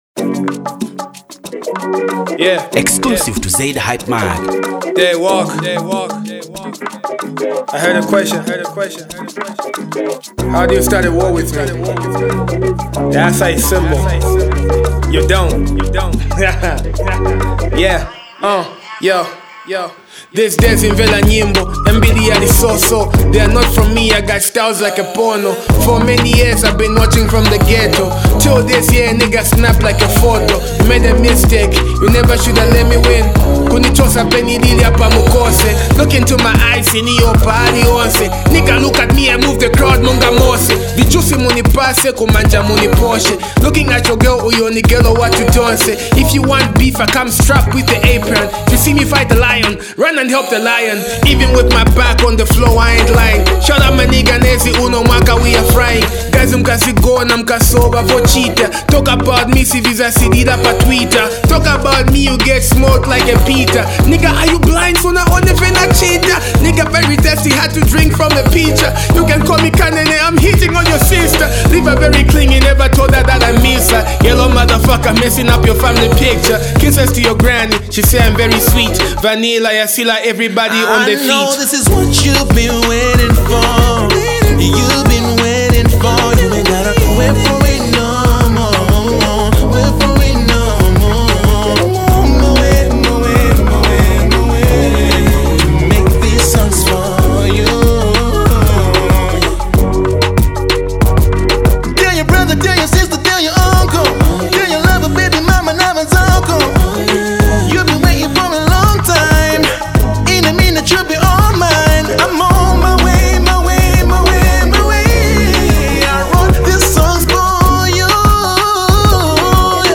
fast rising RnB Vocalist and singer